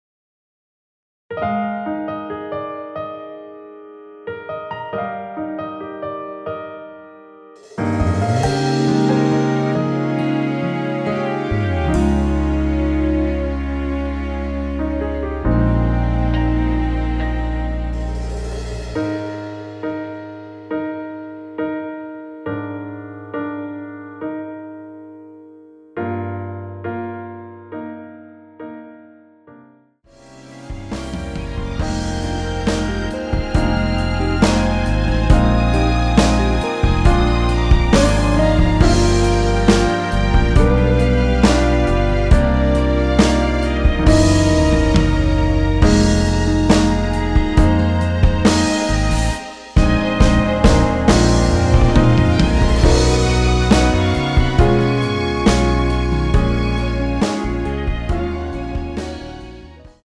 원키 1절후 후렴으로 진행 되는 MR입니다.
Eb
앞부분30초, 뒷부분30초씩 편집해서 올려 드리고 있습니다.
중간에 음이 끈어지고 다시 나오는 이유는